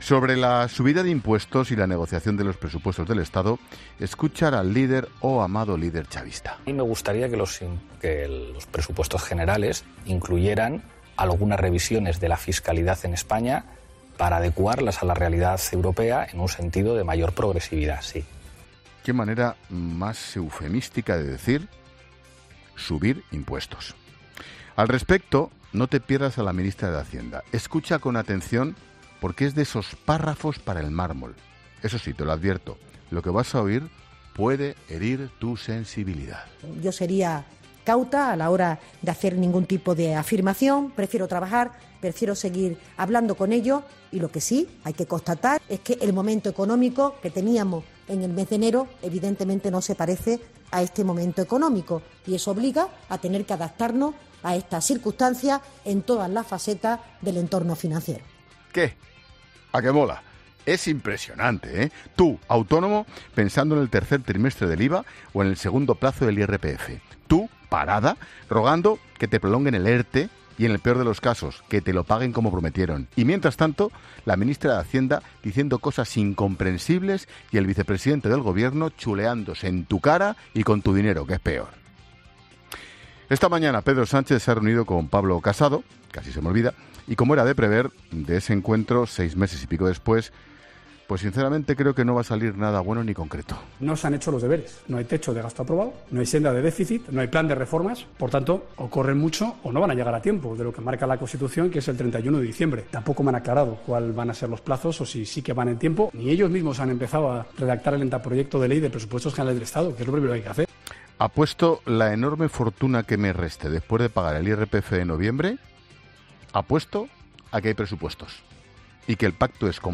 Ángel Expósito ha reflexionado en su monólogo inicial de este miércoles en 'La Linterna' sobre uno de los temas del día: la negociación de los Presupuestos Generales del Estado dentro del propio Gobierno de coalición entre PSOE y Podemos.
Los sonidos del día al respecto los han protagonizado el vicepresidente segundo del Ejecutivo, Pablo Iglesias, y la ministra de Hacienda, María Jesús Montero.